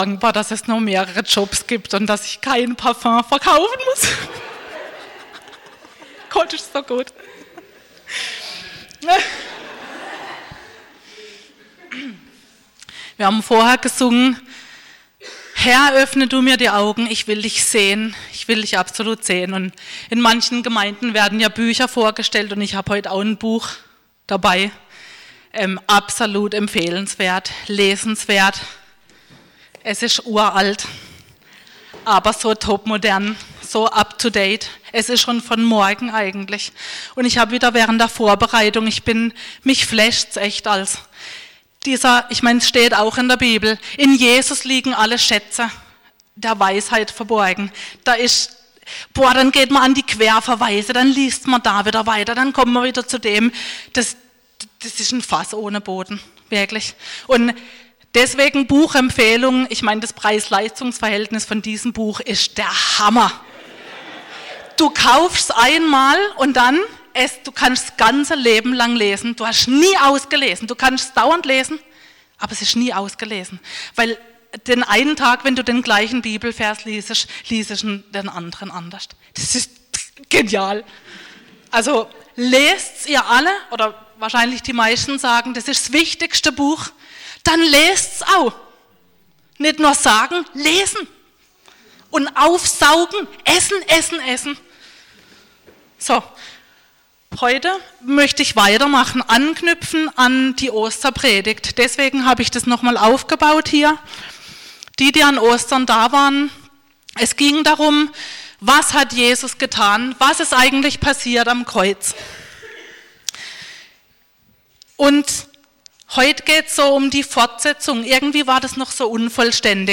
Predigt 26.05.2019